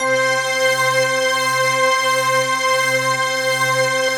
SI1 BELLS08L.wav